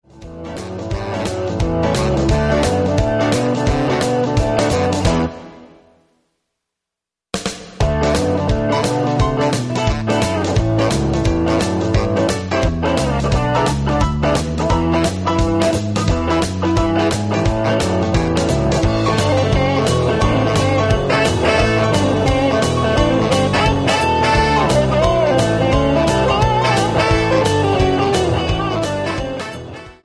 MP3 – Original Key – Backing Vocals Like Original
Pop